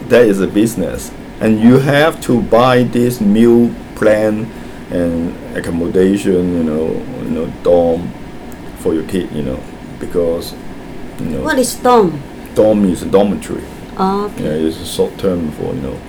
S1 = Taiwanese female S2 = Hong Kong male Context: S2 is talking about tertiary university in America.
It is worth noting that S1 understands plan this time, even though she did not uderstand it earlier (see HK2 : 1969 , because this time it has a clearl [l] in it.